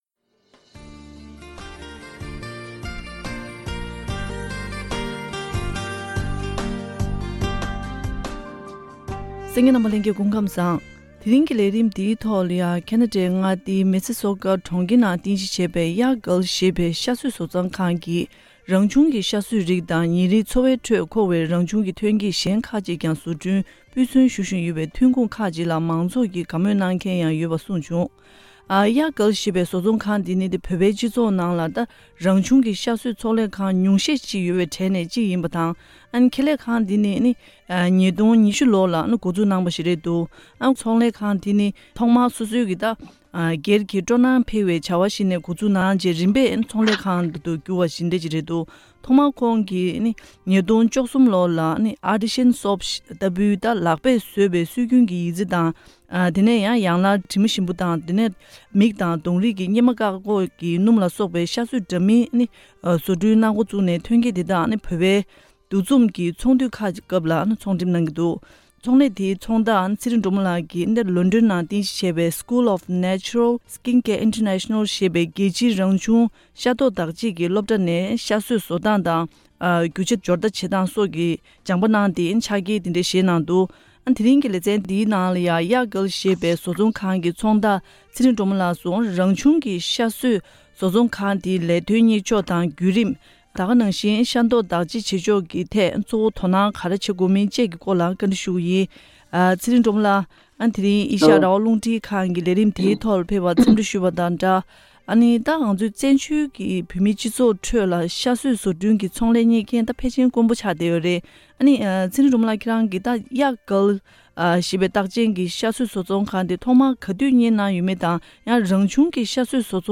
ཐེངས་འདིའི་བཀའ་དྲིའི་ལས་རིམ་ནང་།